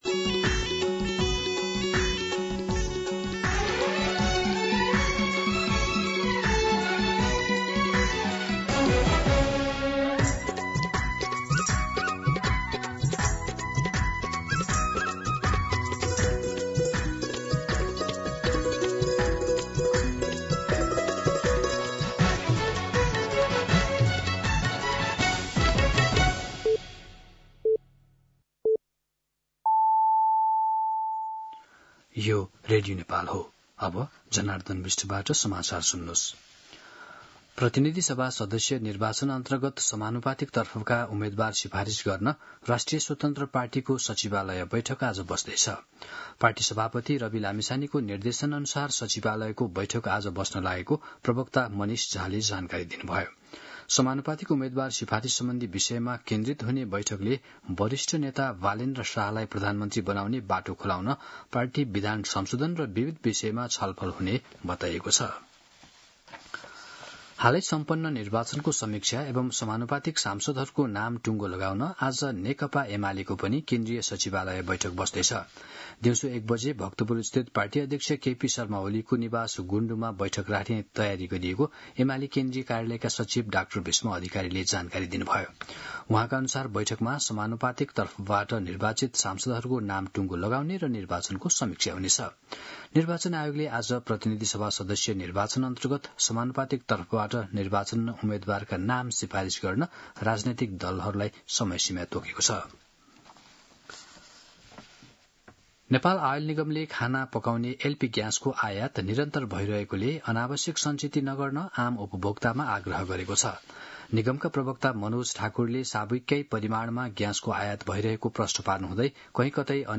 मध्यान्ह १२ बजेको नेपाली समाचार : ३० फागुन , २०८२
12-pm-Nepali-News-1.mp3